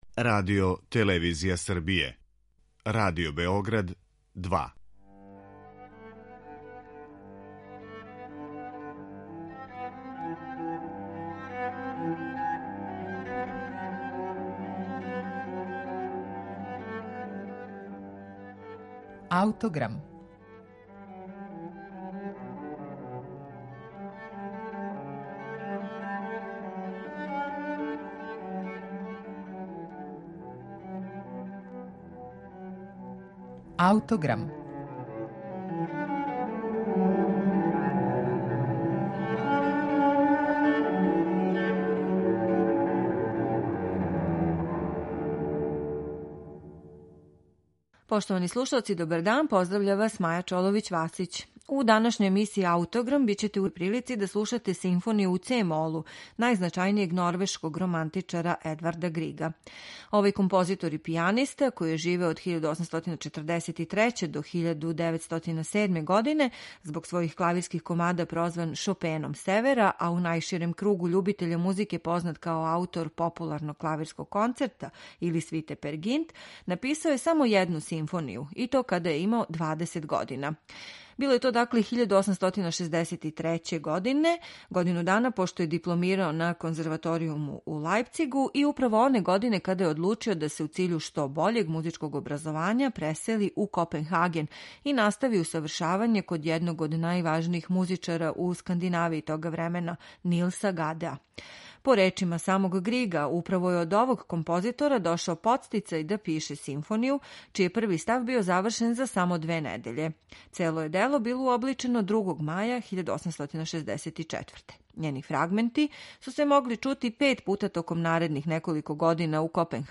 изразити лиризам